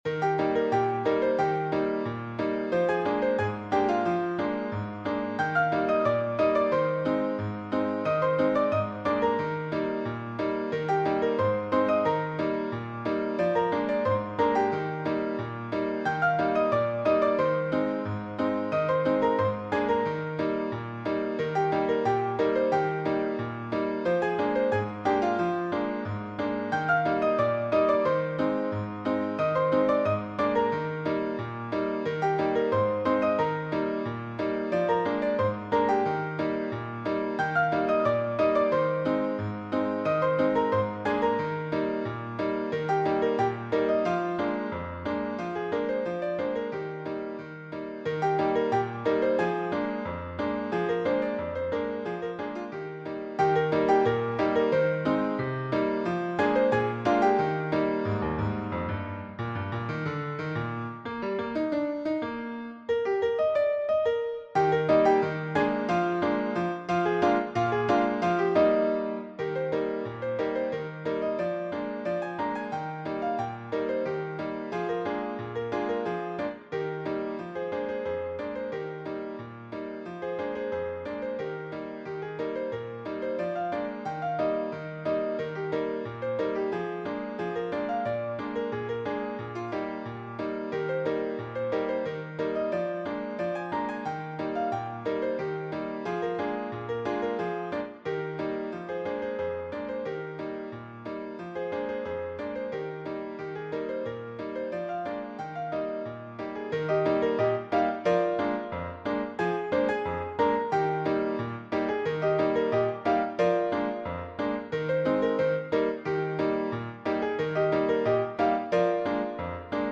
is a lively ragtime piece
Cheerful
Classic ragtime
Piano only